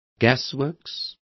Complete with pronunciation of the translation of gasworks.